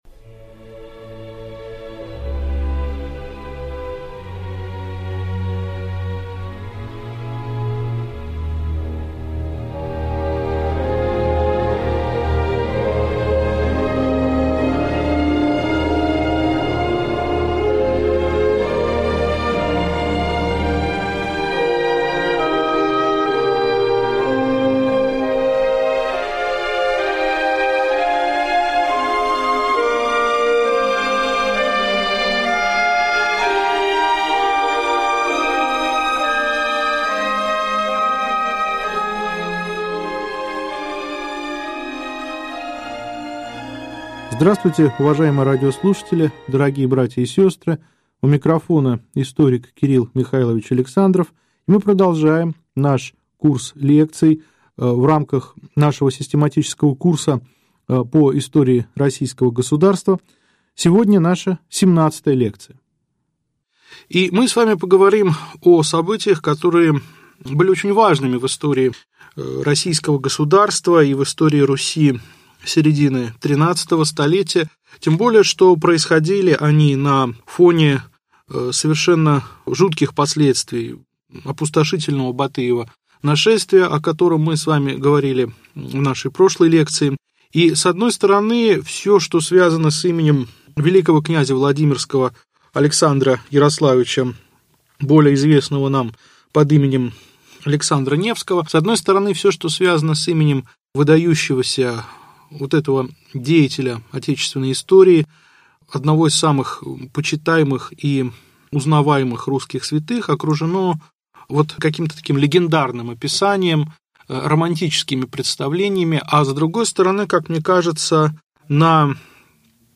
Аудиокнига Лекция 17. Св. блгв. Вел. кн. Александр Невский | Библиотека аудиокниг